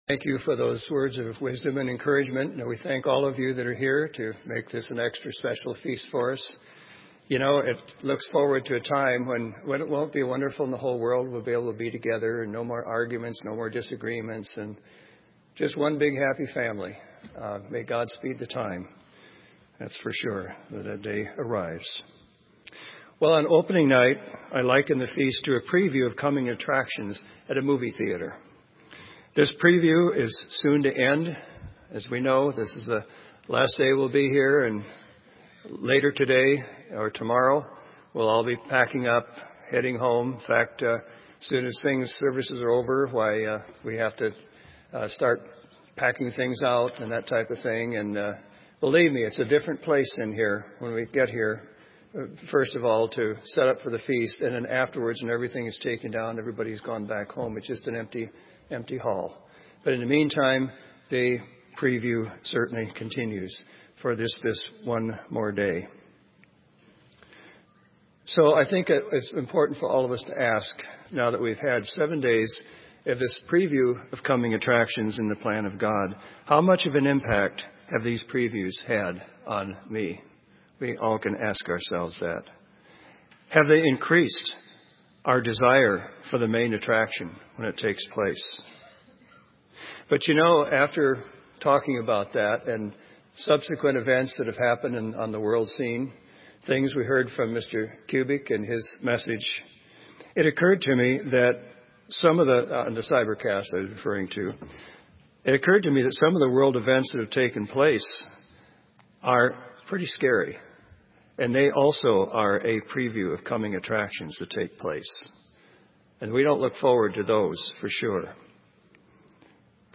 This sermon was given at the Bend, Oregon 2014 Feast site.